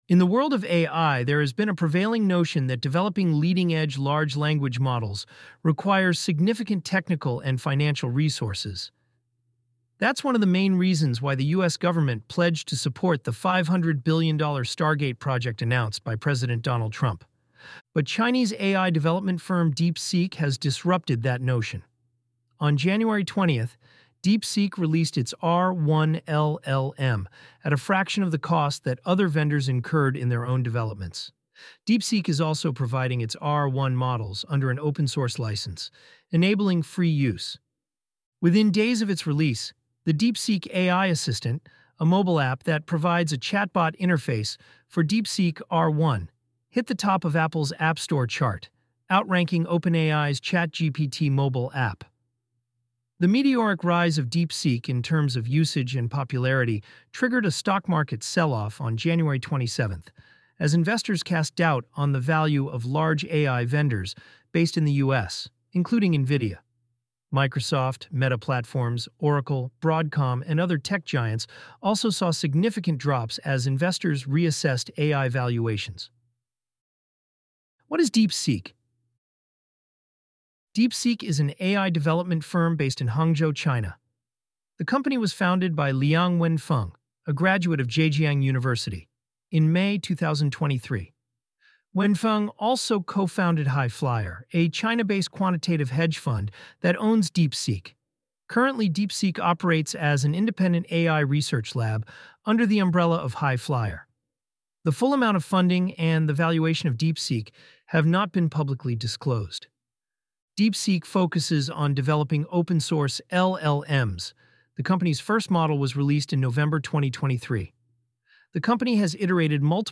Este audio fue generado por IA